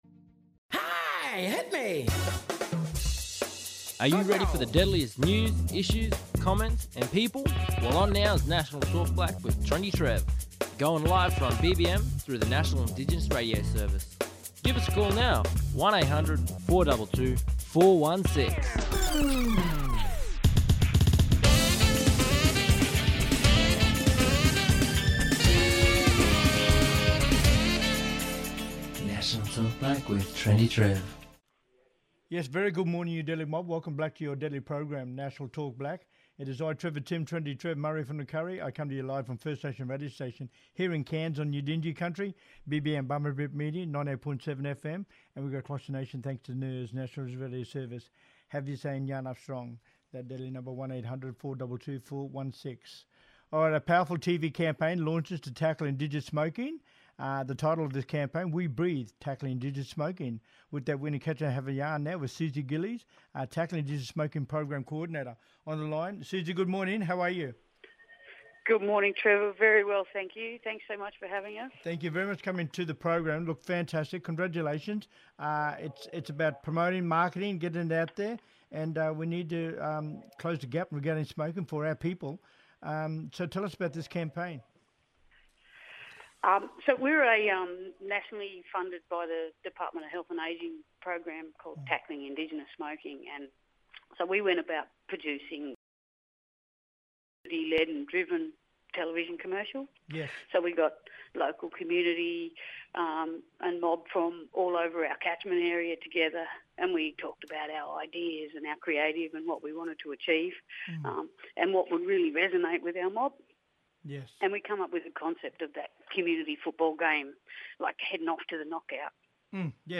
On todays National Talk Black via NIRS – National Indigenous Radio Service we have: